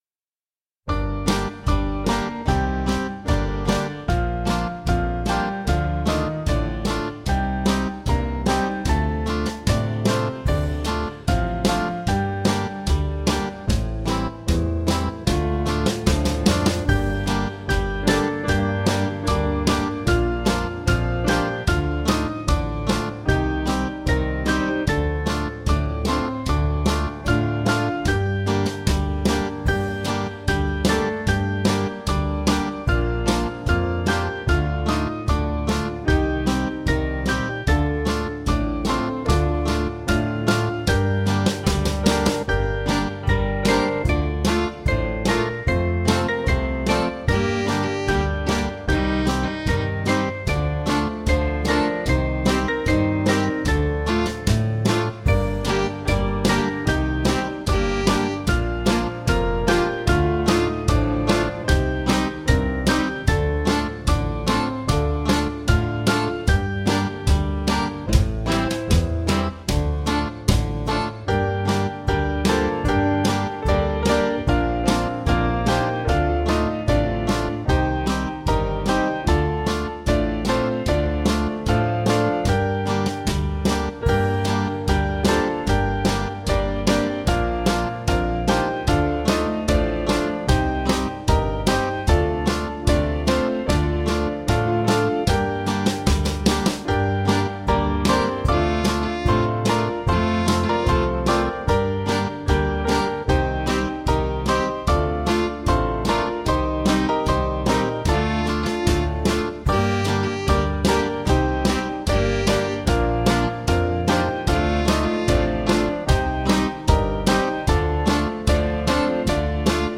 Small Band
(CM)   5/Gm-Abm
Jewish Feel   501.6kb